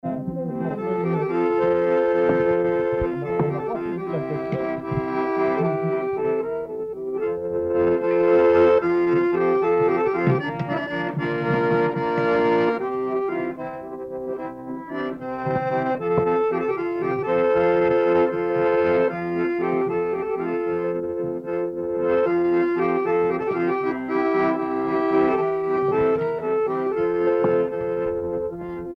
Air
Saint-Nicolas-la-Chapelle
Pièce musicale inédite